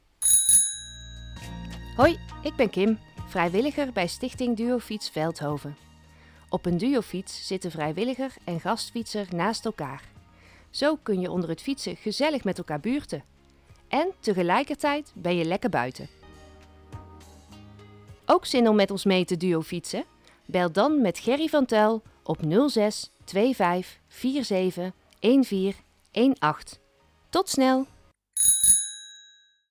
Reclamespot-Duo-fiets-Veldhoven.mp3